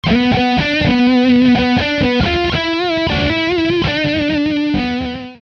COMPRESSEUR
Le compresseur accentue les sons les plus faibles et atténuent les sons les plus forts, ce qui permet de donner une certaine homogénéité et surtout beaucoup de sustain.
withcompress.mp3